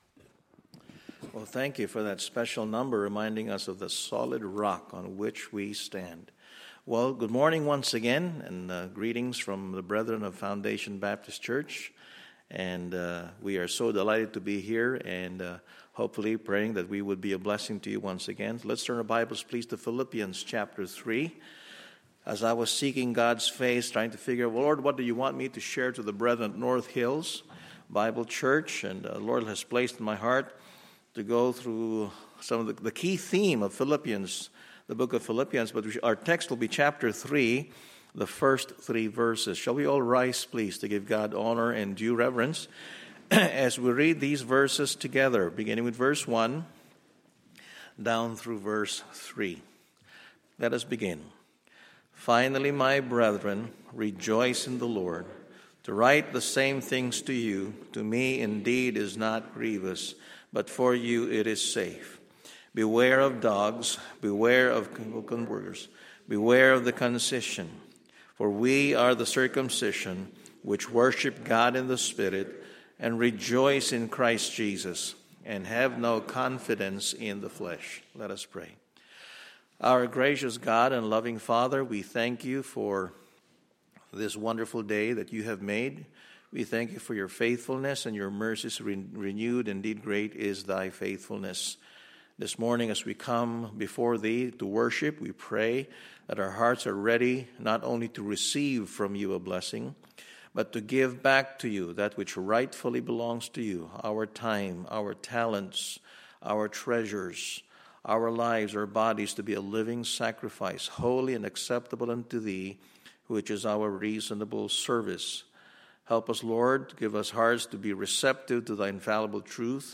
Sunday, September 15, 2013 – Morning Service